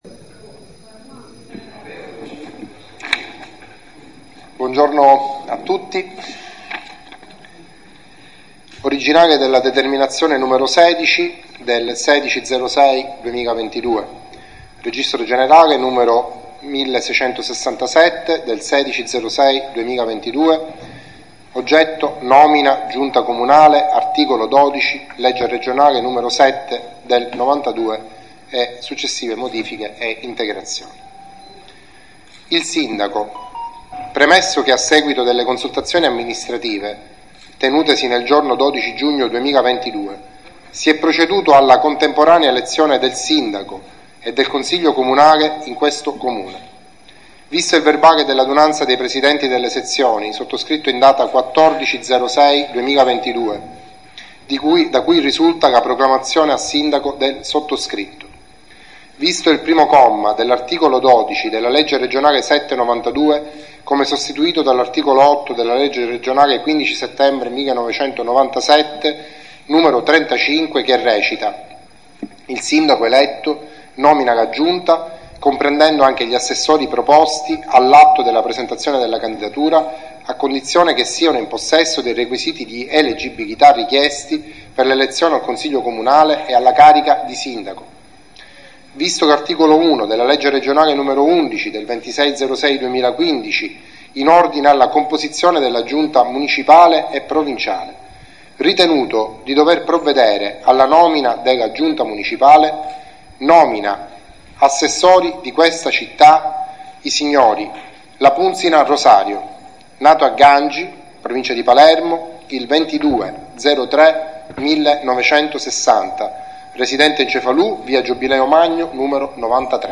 Si è svolta nella sala delle Capriate del Comune di Cefalù la cerimonia di giuramento degli Assessori della Giunta nominata dal Sindaco Daniele Tumminello, eletto domenica 12 giugno 2022 a Cefalù.
SINDACO TUMMINELLO - LETTURA DETERMINA N. 16 DI NOMINA DEGLI ASSESSORI
Intervento_Sindaco.mp3